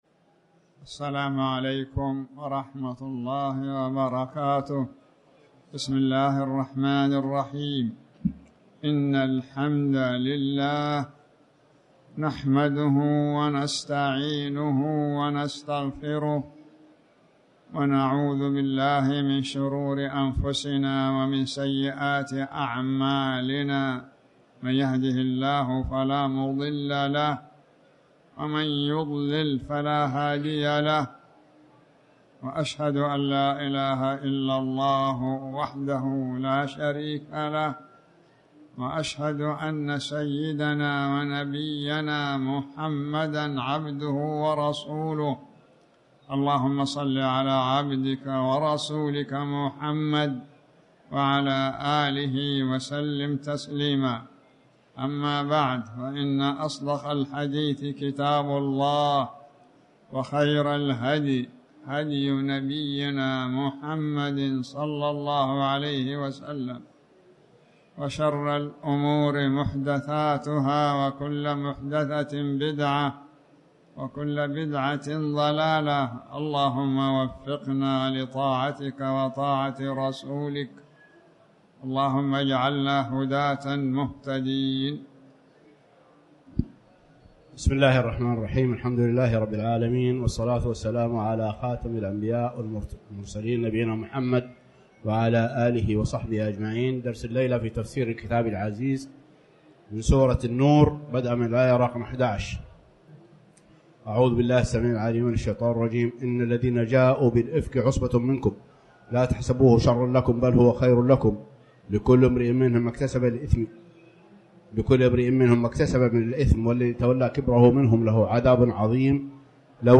تاريخ النشر ٦ ربيع الأول ١٤٤٠ هـ المكان: المسجد الحرام الشيخ